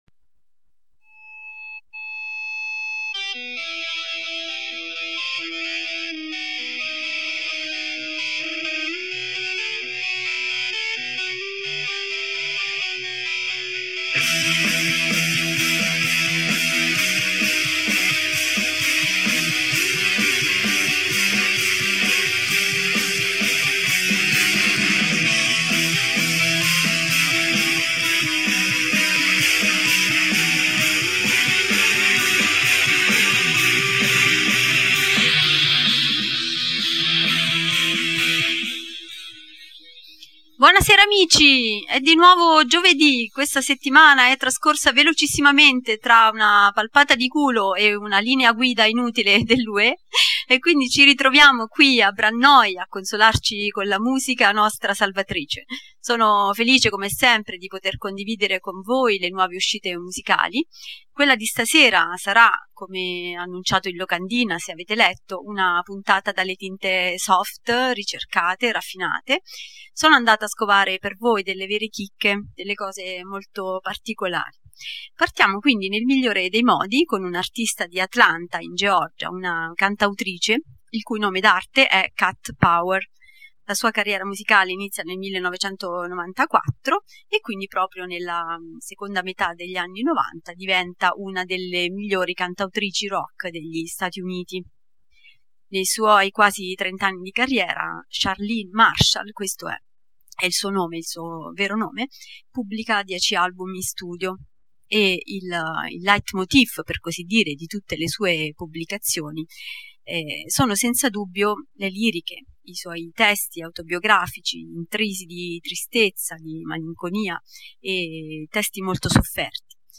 I paesaggi di questa quinta puntata saranno ricercati, le atmosfere sognanti e raffinate, gli orizzonti distopici e rarefatti. Entreremo in un mondo fatto di vuoti, di forze, di vivi o morti, di porti, di malinconie, di navigazioni perenni.